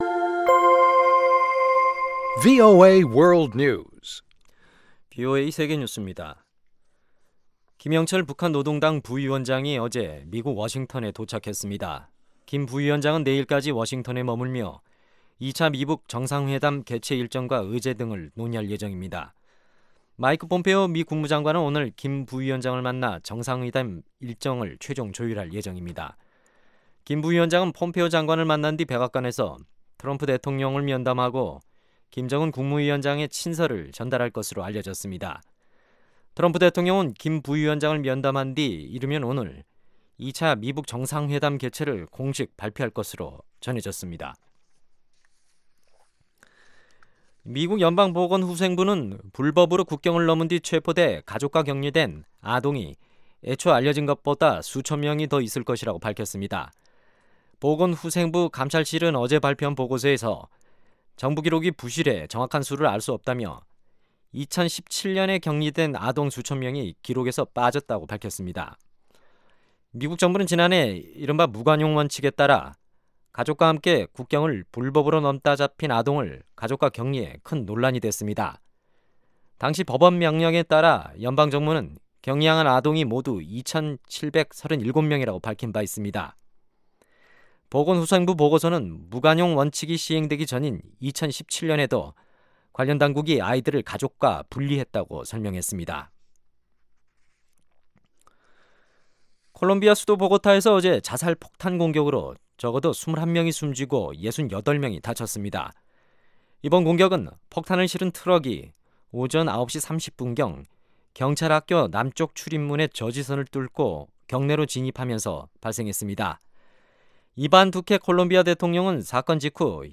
세계 뉴스와 함께 미국의 모든 것을 소개하는 '생방송 여기는 워싱턴입니다', 2019년 1월 18일 저녁 방송입니다. ‘지구촌 오늘’은 미 백악관이 다음 주부터 스위스에서 열리는 세계경제포럼(WEF) 연차총회에 미국 대표단의 파견을 취소했다는 소식, ‘아메리카 나우’에서는 연방 정부 셧다운을 둘러싸고 백악관과 민주당 간 대립이 깊어지는 가운데 국무부가 외교관 대부분을 업무 복귀시킬 계획이라는 이야기를 전해드립니다.